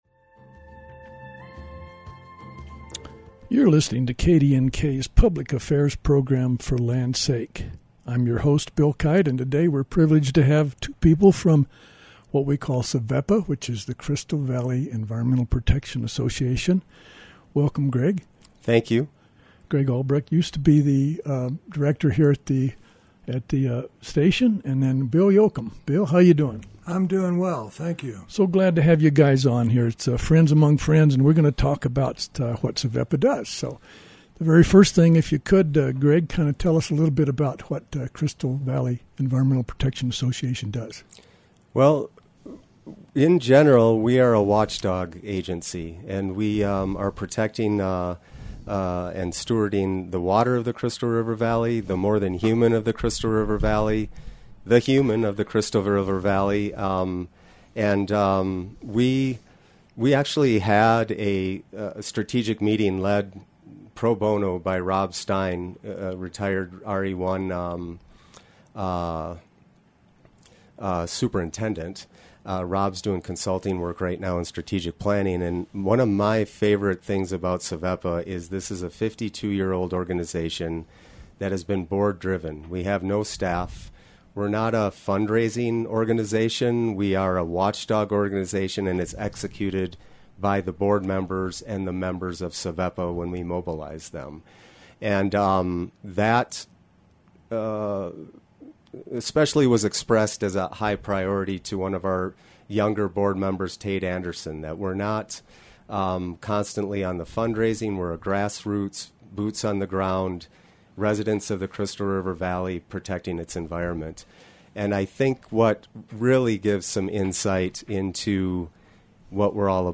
a show featuring guests sharing thoughts on their personal connection to the land.